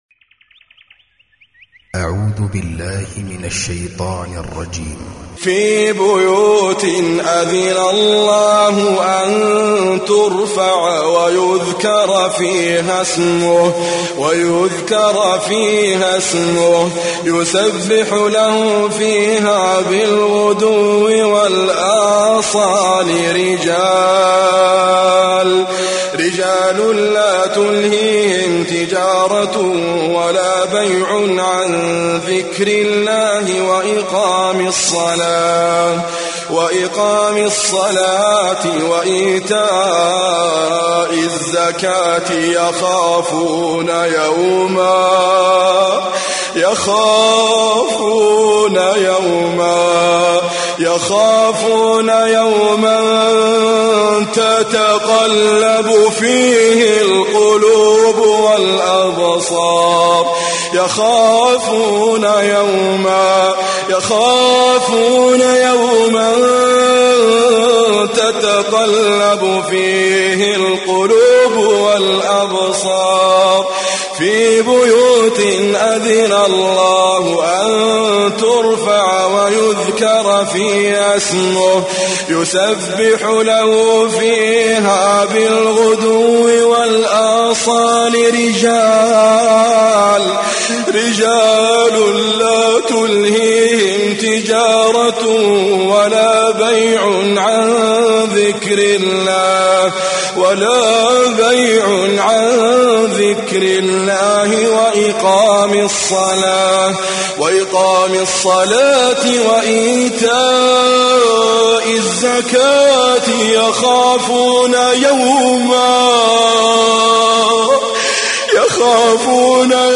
Recitime
Idriss Abkar